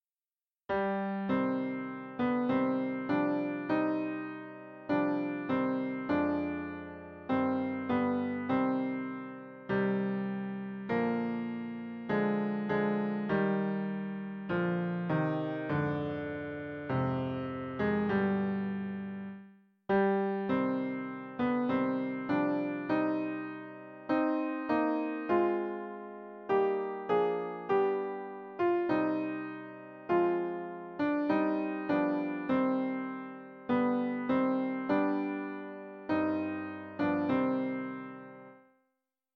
050.b-Það árlega gerist (TTBB)